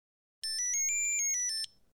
Device-connected-to-patient
Device-connected-to-patient.mp3